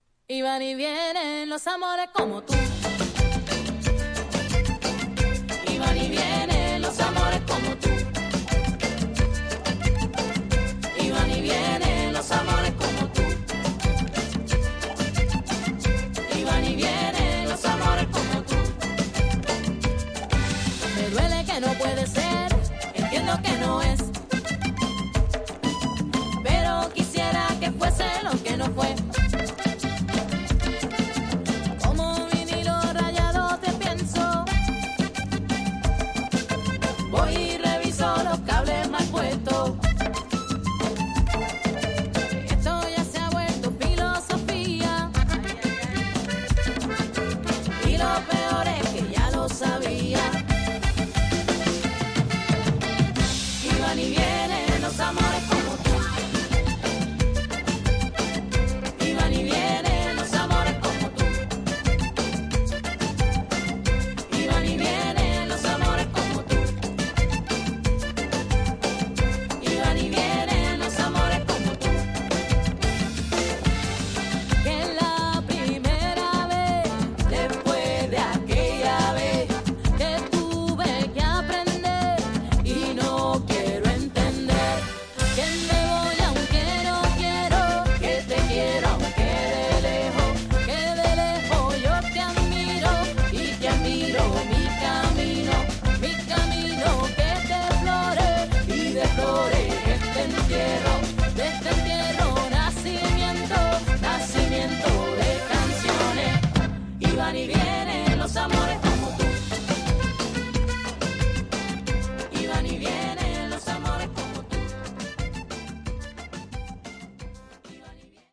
Tags: Tropical , Spain